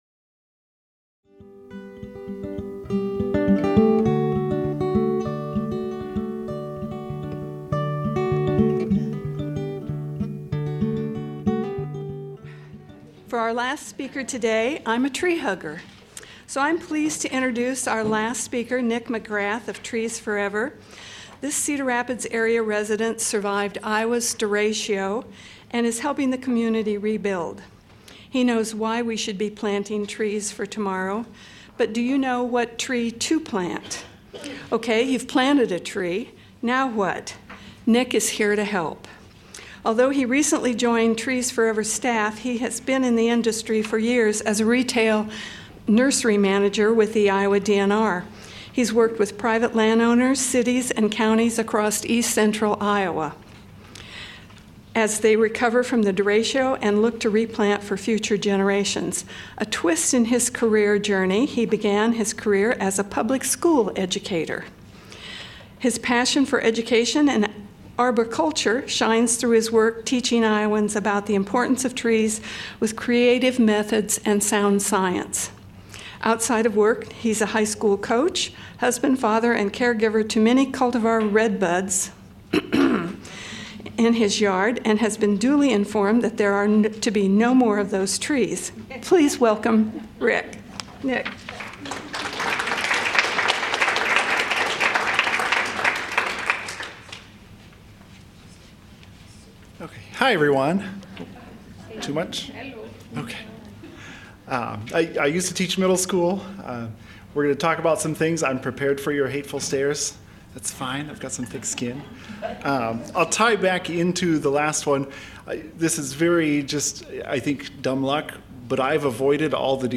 This presentation is part of the Johnson County Master Gardeners 2023 Continuing Education Series.